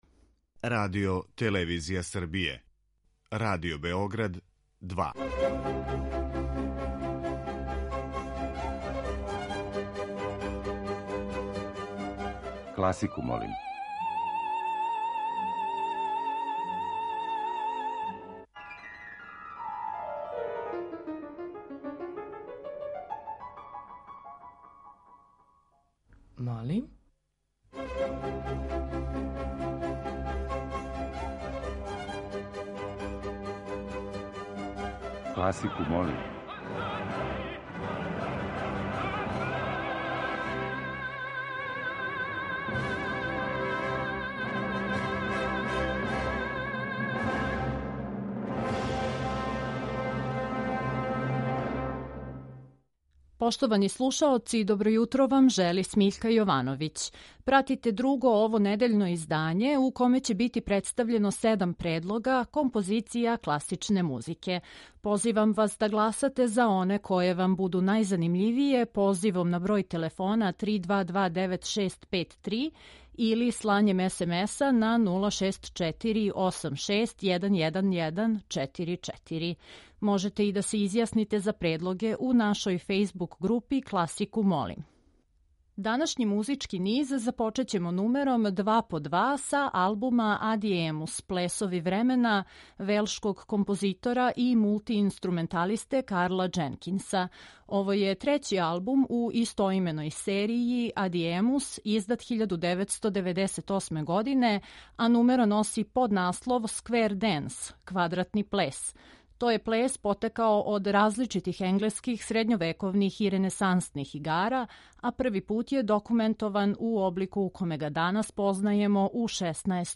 И ове седмице слушаоцима ће бити понуђени разноврсни предлози из домена класичне музике.